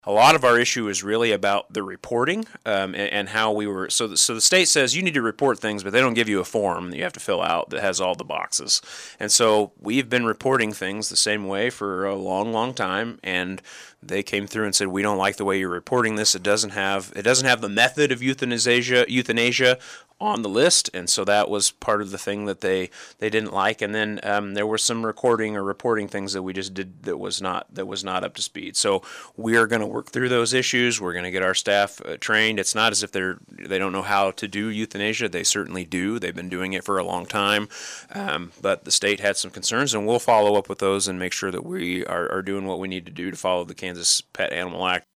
City Manager Jacob Wood joined in on the KSAL Morning News Extra with a look at a range of issues that included a deep dive on how the shelter received an unsatisfactory grade during a January 12th visit from KDA.